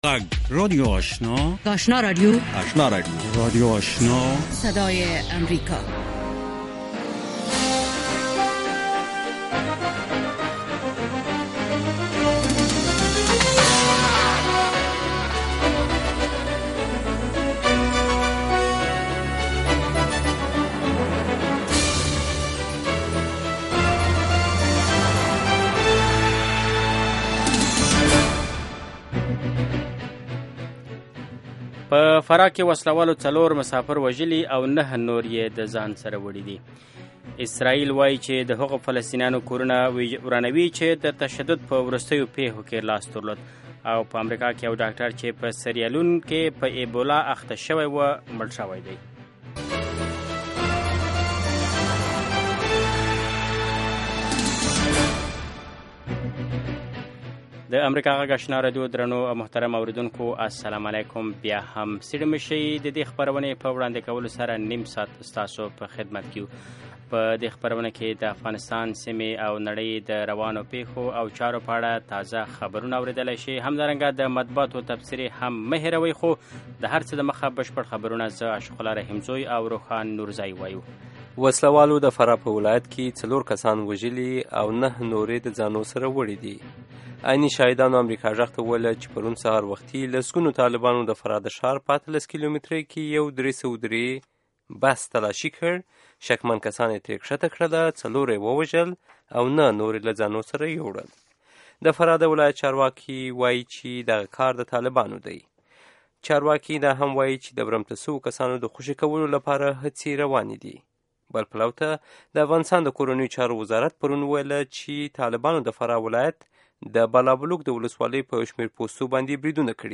دویمه سهارنۍ خبري خپرونه
په دې نیم ساعته خپرونه کې د افغانستان او نورې نړۍ له تازه خبرونو وروسته مهم رپوټونه او مرکې اورېدای شئ.